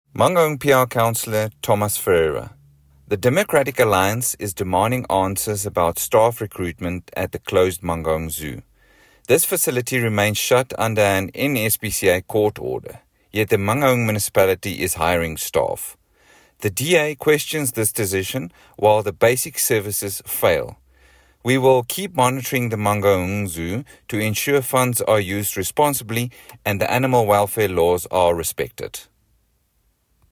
English and Afrikaans soundbites by Cllr Thomas Ferreira and